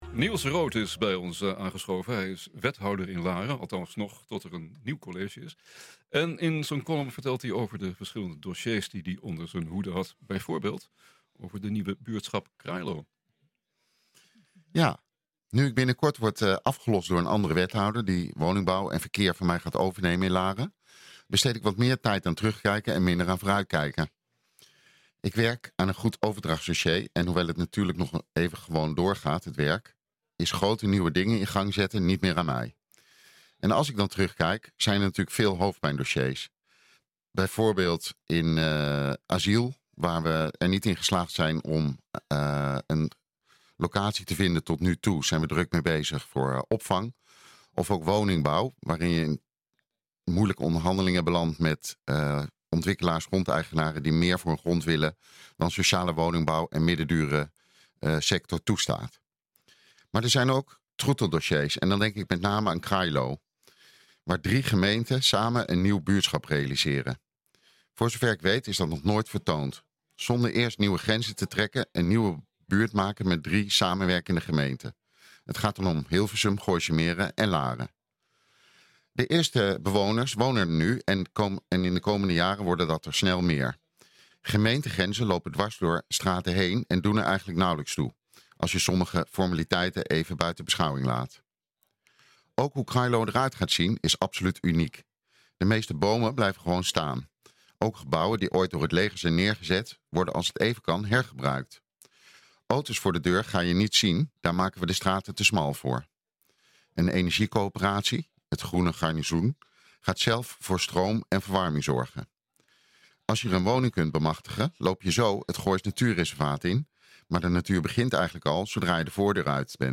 NH Gooi Zaterdag - Column door Niels Rood